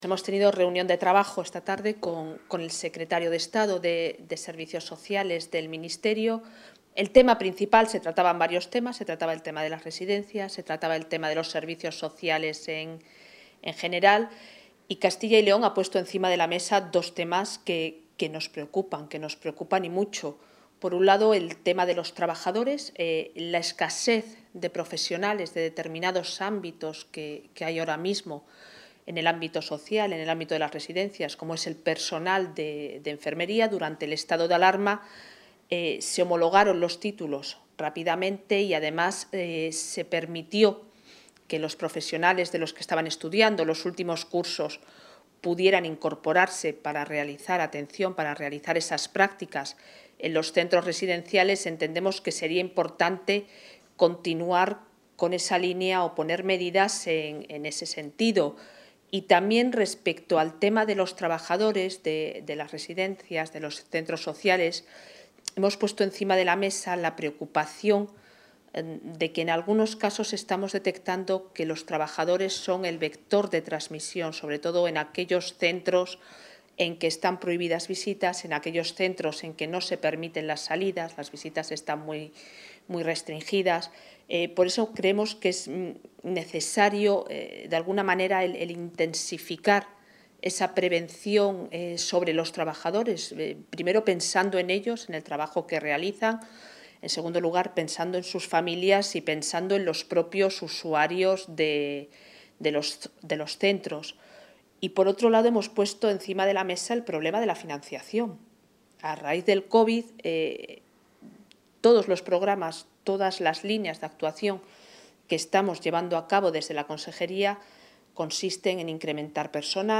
Declaraciones de la consejera de Familia e Igualdad de Oportunidades tras la reunión del grupo de trabajo ‘Residencias y COVID-19’